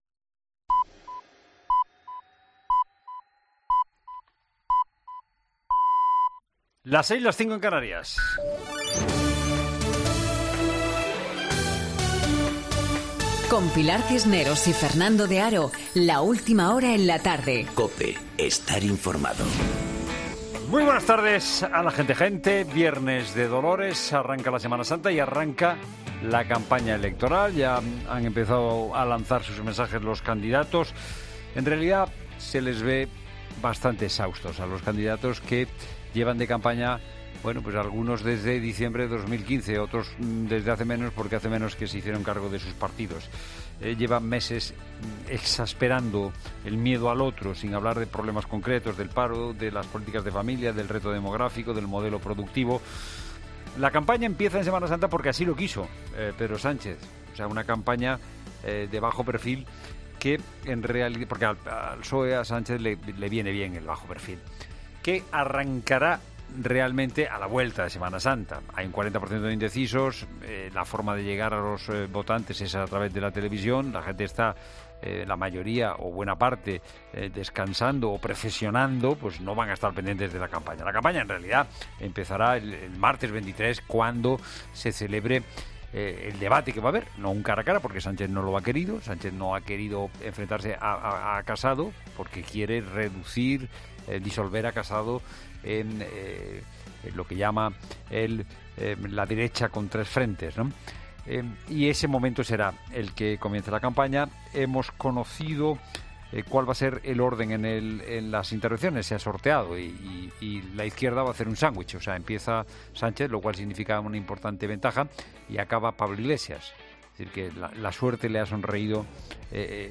es un magazine de tarde que se emite en COPE